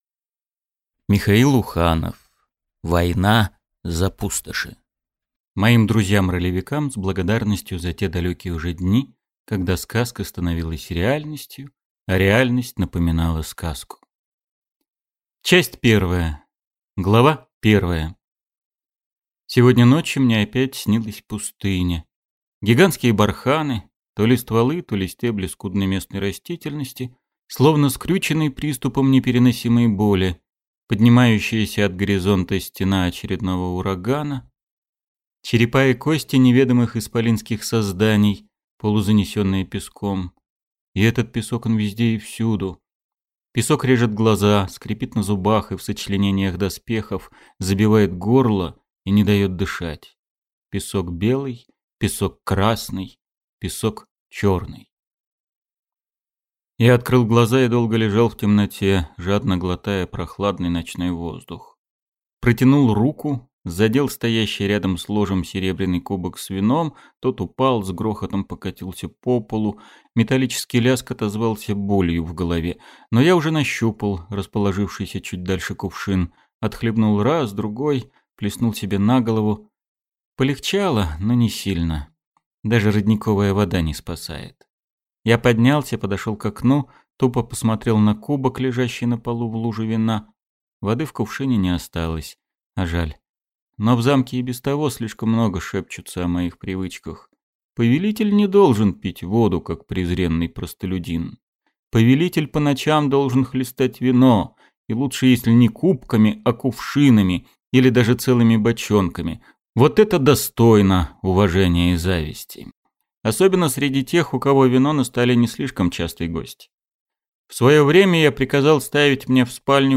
Аудиокнига Война за Пустоши | Библиотека аудиокниг